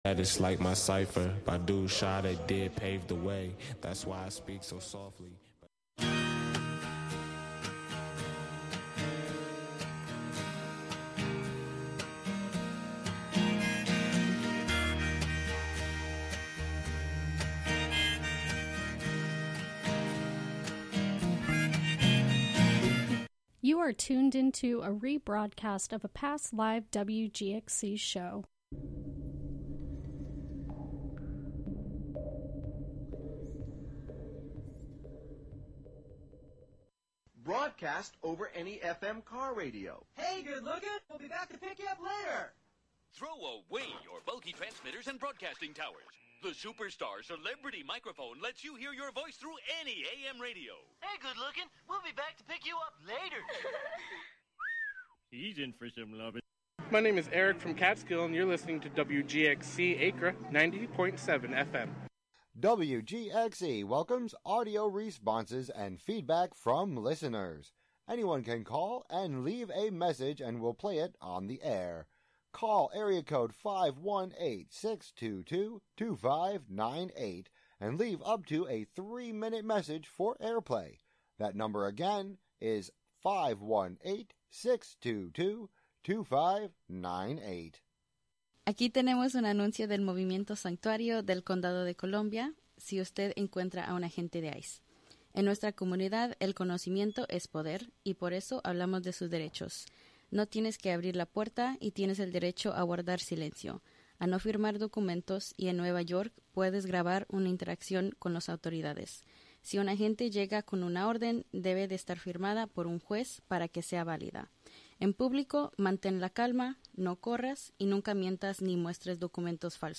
The show honors the necessity to centralize music and sound in decolonizing and indigenizing culture. It also amplifies Indigenous music traditions to bring attention to their right to a sustainable future in the face of continued violence and oppression.